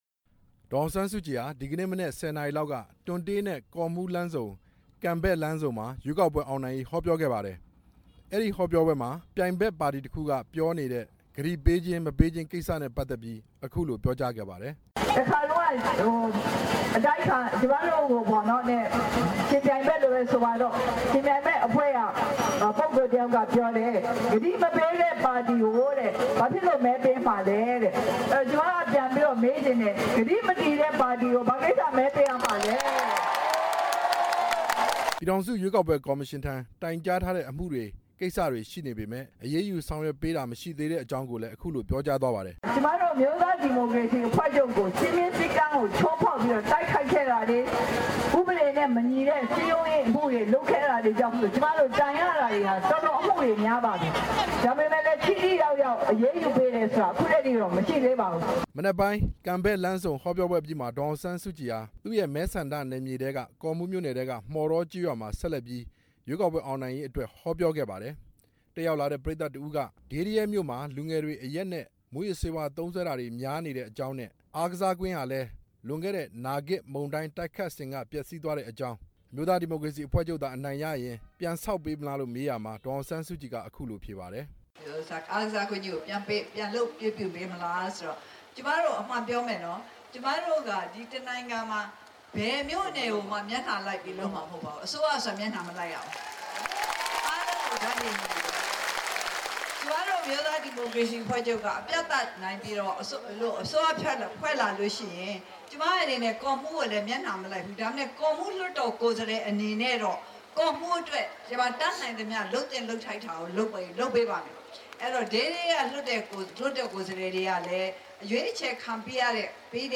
ဒေါ်အောင်ဆန်းစုကြည်ရဲ့ ကော့မှူးမြို့နယ်က ဟောပြောပွဲ တင်ပြချက်